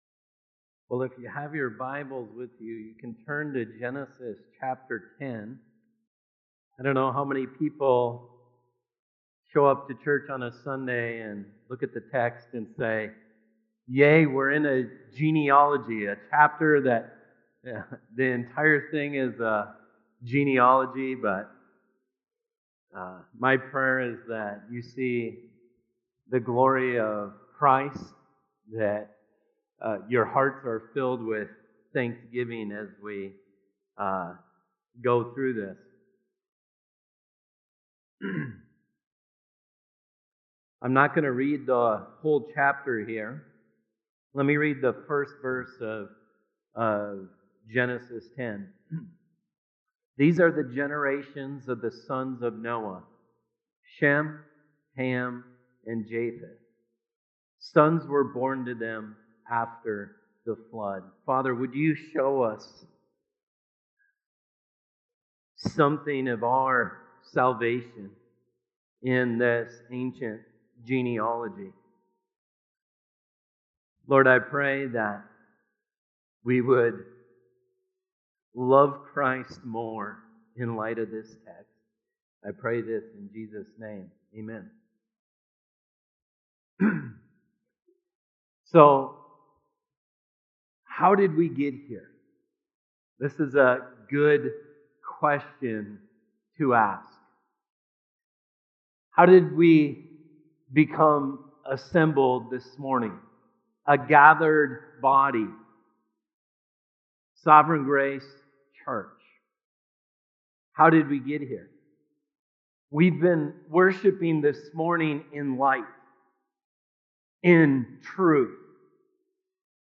Sovereign Grace Sermon Podcasts